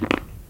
文件夹里的屁 " 屁 42
描述：从freesound上下载CC0，切片，重采样到44khZ，16位，单声道，文件中没有大块信息。
Tag: 喜剧 放屁 效果 SFX soundfx 声音